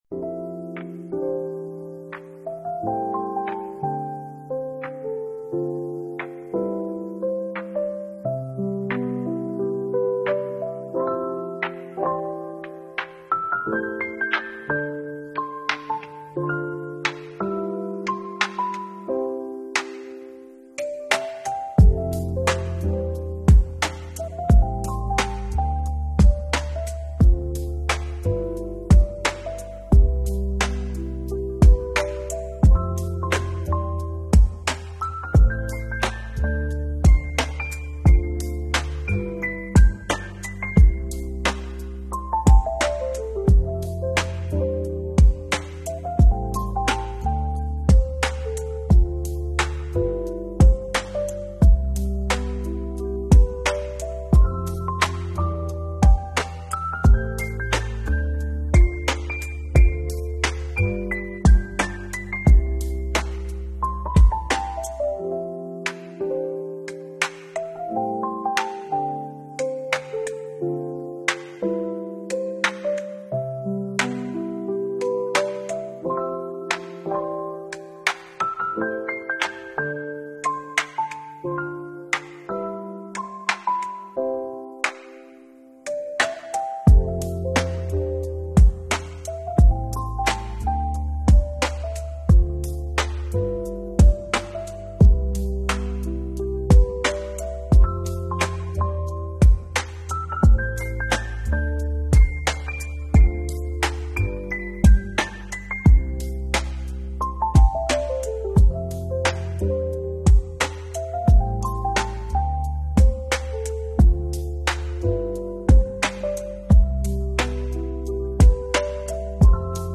Jazz Fusion BGM
sparkling rhythms